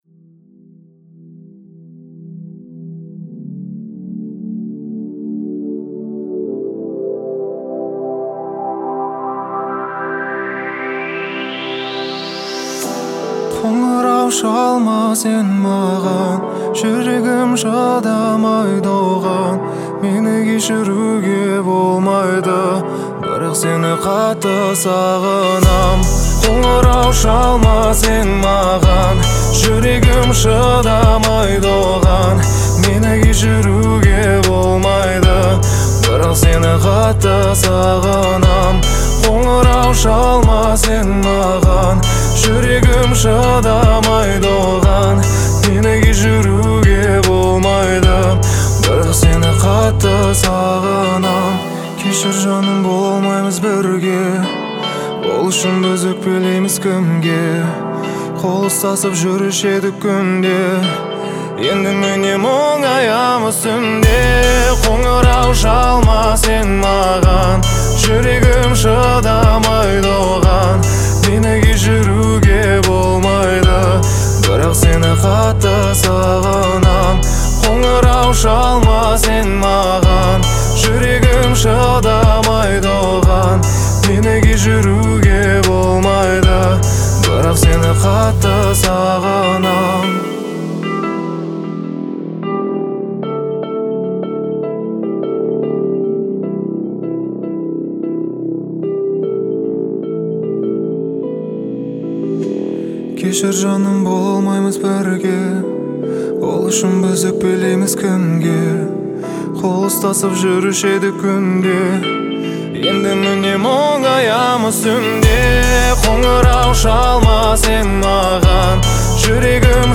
выразительным вокалом, который передает искренность и боль.